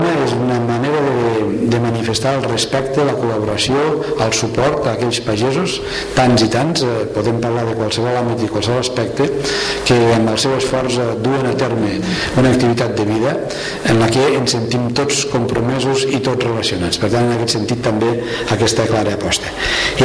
El president de la Diputació de Lleida, Joan Reñé, ha defensat el consum dels productes de proximitat que elaboren els pagesos de les comarques lleidatanes en l’acte de cloenda de la 60a Fira Agrària de Sant Miquel i 29è Saló Eurofruit, que ha estat presidida pel director general de Producció i Mercats Agraris del Ministeri d’Agricultura, Alimentació i Medi Ambient, Fernando Miranda Sotillos.
Joan-Re--e-cloenda-st-Miquel.mp3